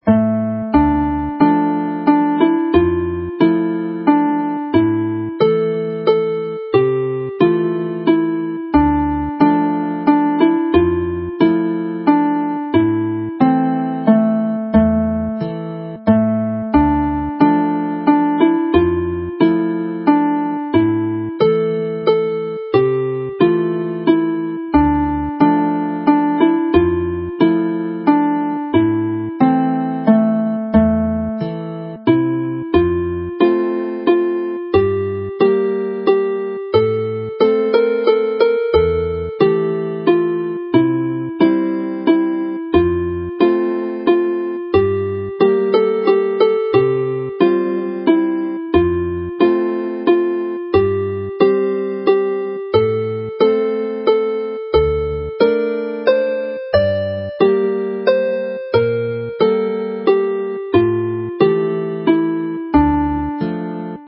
Daw'r Gaeaf (the winter is coming) has the traditional haunting Welsh AABA structure with the first part A in a minor key (Dm) which is restated, then in part Bgoes into the relative major (F) before reverting to the minor of part A to finish.